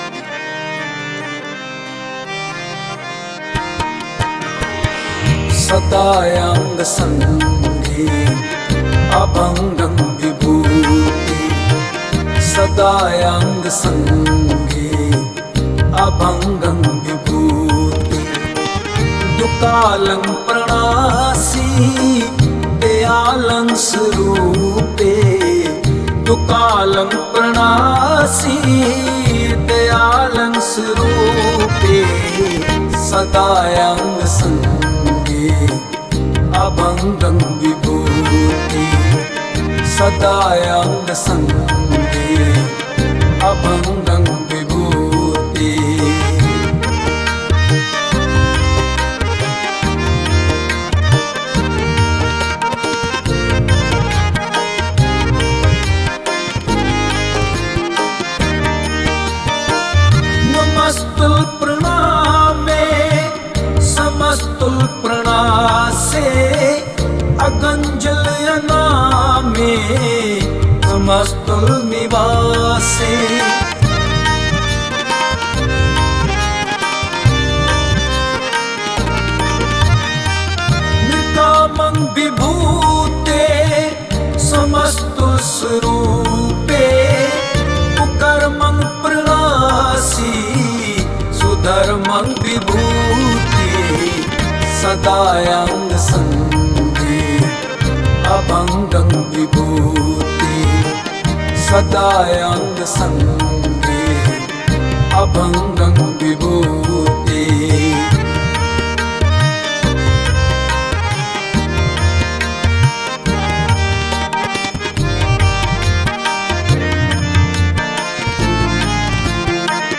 Gurbani Kirtan